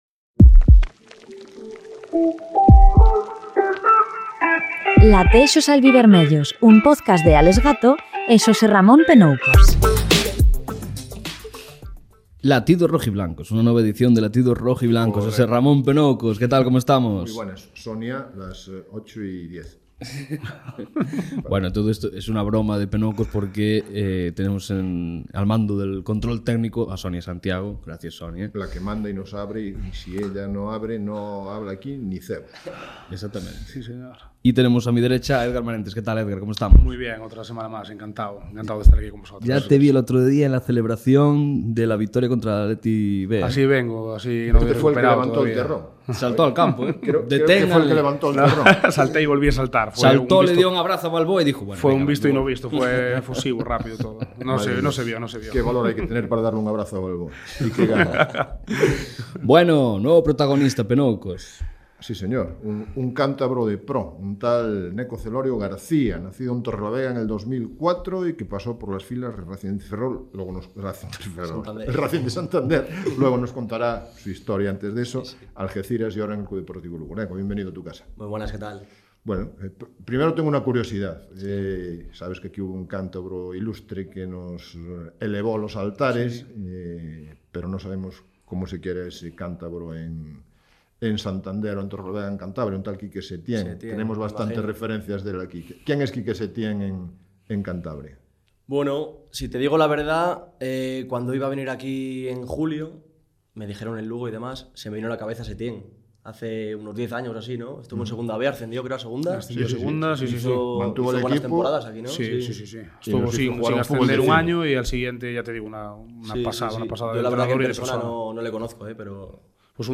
Unha conversa tranquila, sincera e de moito fútbol.